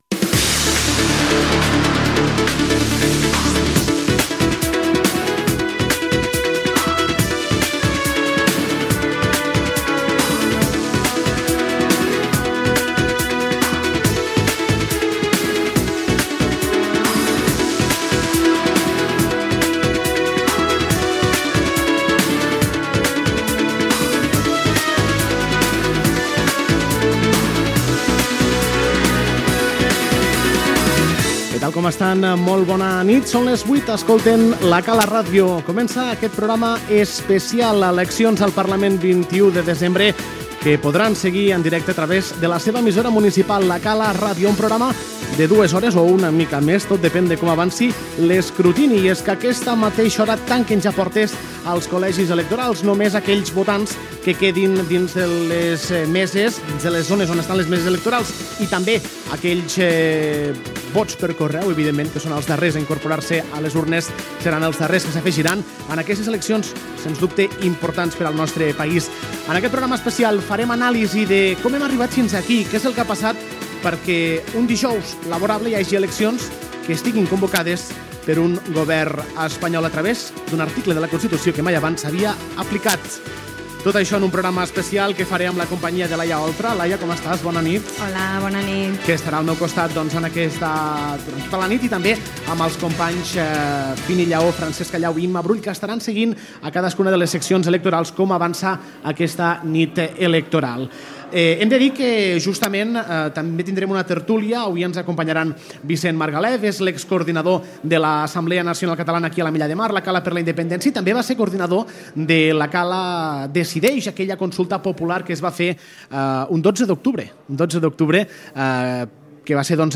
Sintonia, presentació del programa especial eleccions generals 2017: equip, invitats previstos, dades de la participació
Informatiu
FM